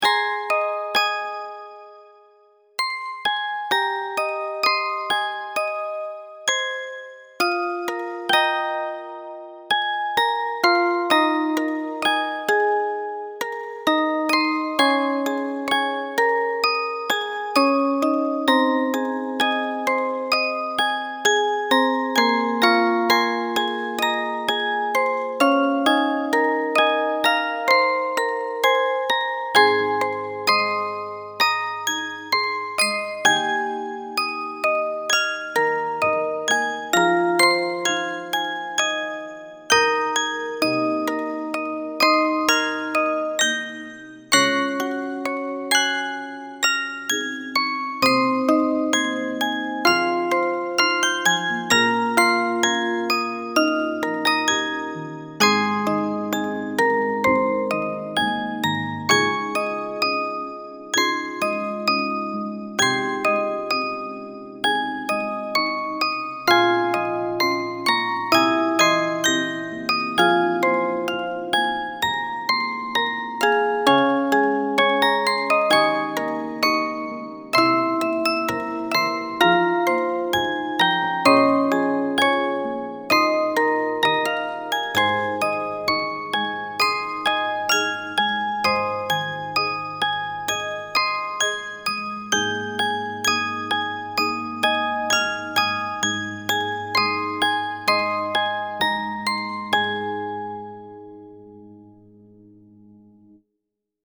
オルゴール 幻想的 穏やか